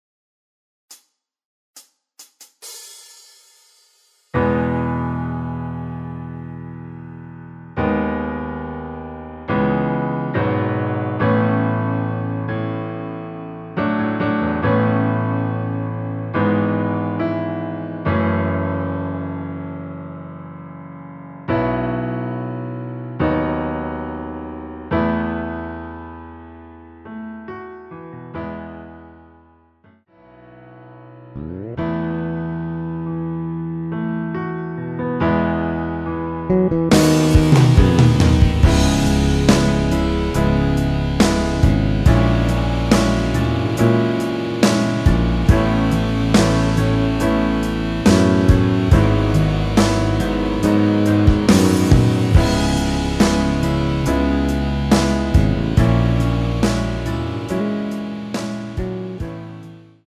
전주없이 노래가시작되는곡이라 카운트 만들어 놓았습니다.
앞부분30초, 뒷부분30초씩 편집해서 올려 드리고 있습니다.